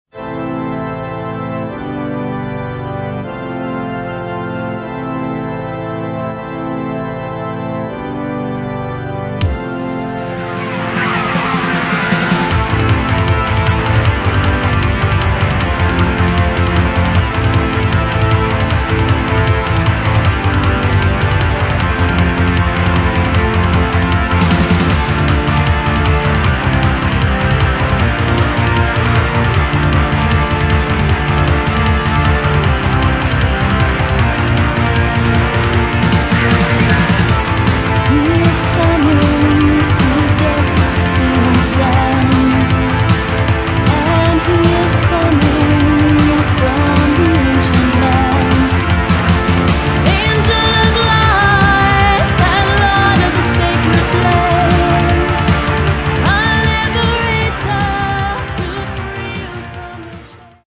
Рок
демонстрирует более мрачную и задумчивую сторону группы
вокал, флейта
гитара, программинг, композиция, вокал